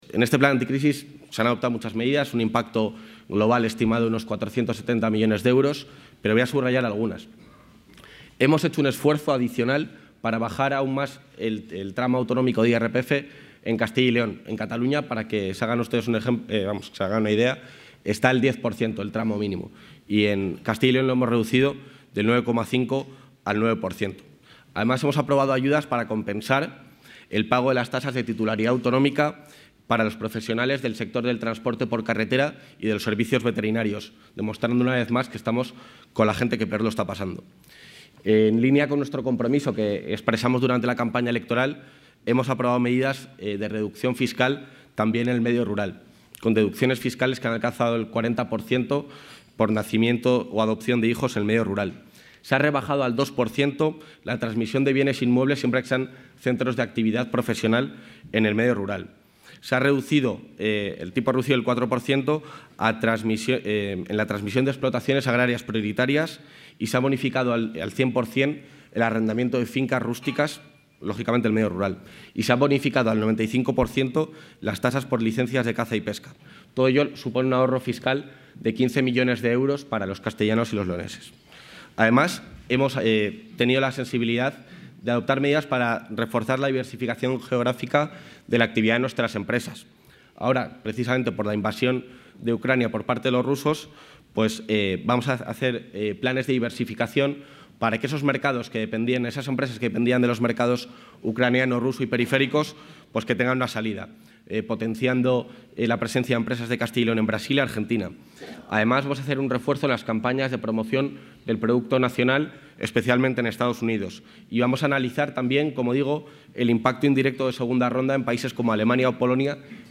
Material audiovisual sobre la intervención del vicepresidente de la Junta en el desayuno informativo de Nueva Economía Fórum
Audio del vicepresidente de la Junta.